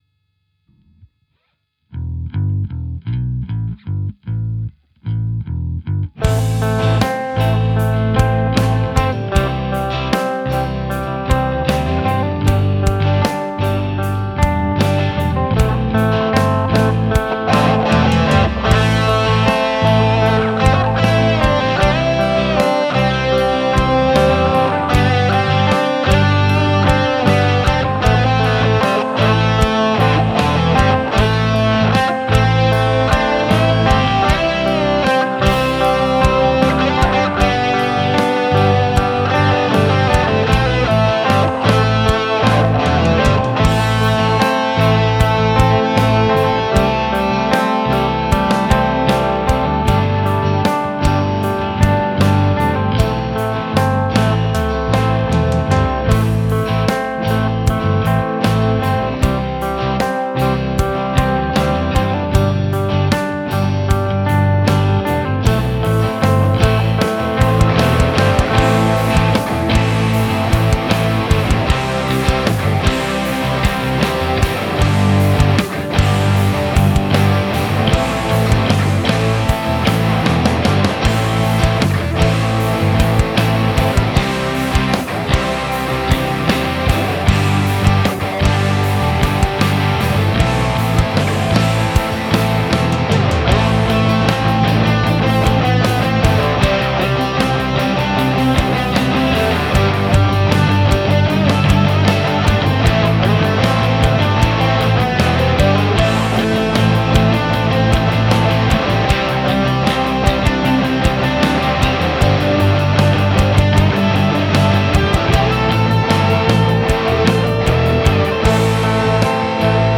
3 solos, all of which i wrote on my first month playing guitar.